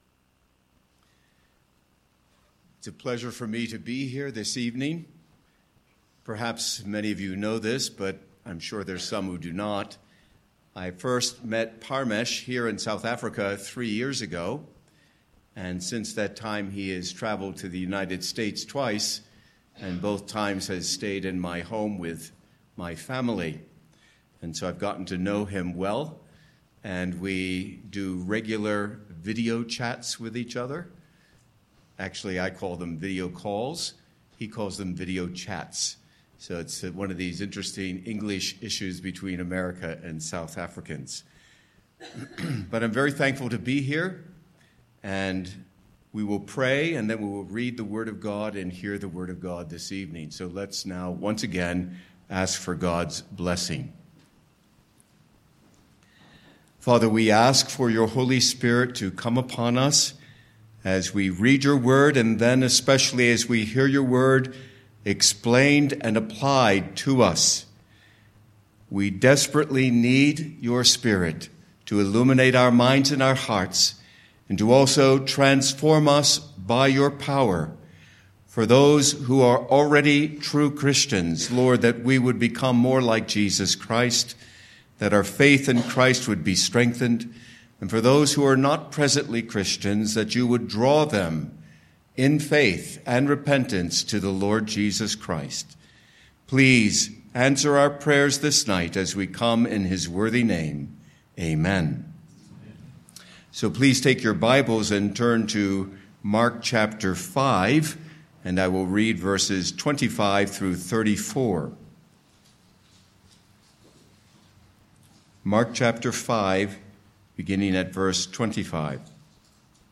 Sermon points: 1. The woman’s Dreadful Condition
Mark 5:25-34 Service Type: Evening Passage